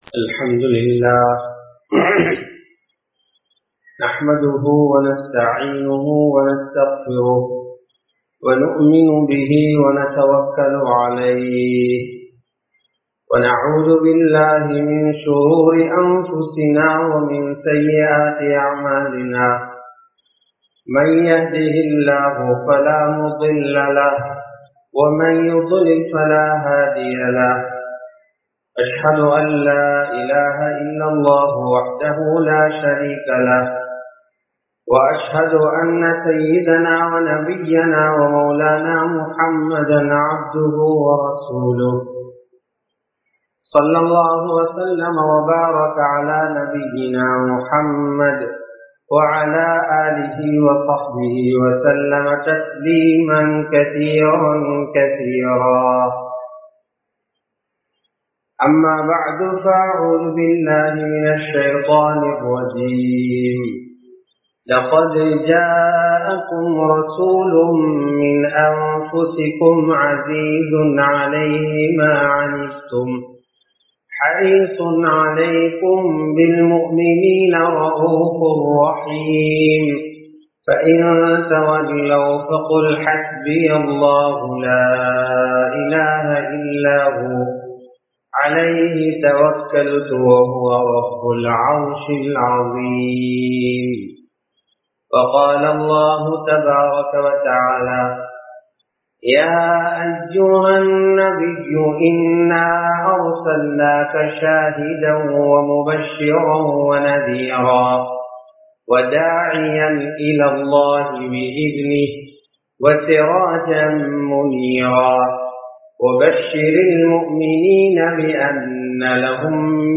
நபி (ஸல்) அவர்களின் பிறப்பும் நபித்துவமும் | Audio Bayans | All Ceylon Muslim Youth Community | Addalaichenai